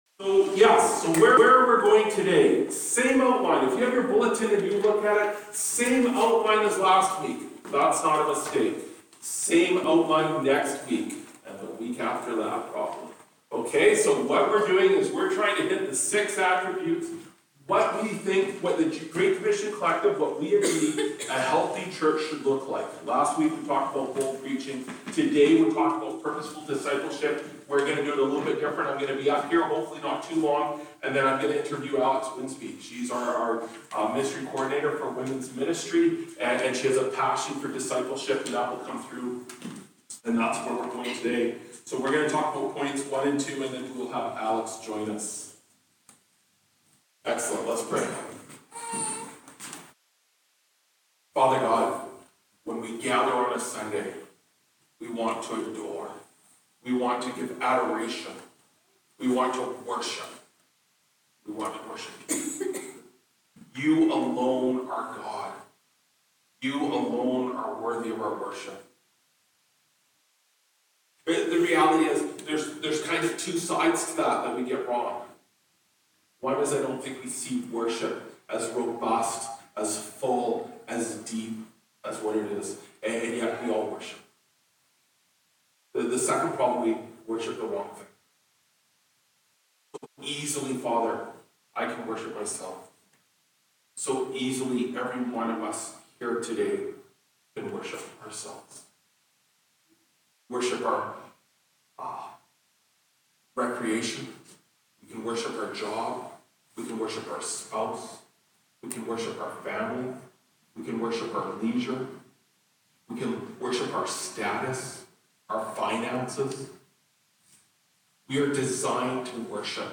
Oct 27, 2024 Purposeful Discipleship (1 Thessalonians 2:1-13) MP3 SUBSCRIBE on iTunes(Podcast) Notes Discussion Sermons in this Series This sermon was recorded in Salmon Arm and preached in both SA and Enderby.